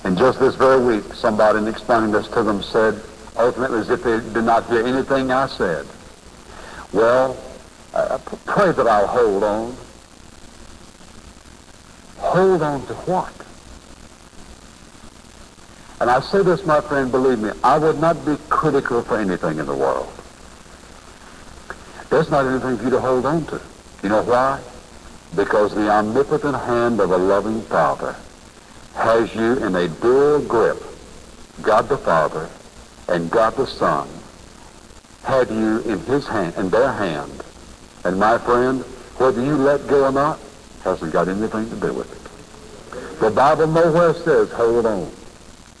Do we trust DR. Charles Stanley because he has degrees and he's preaching to millions?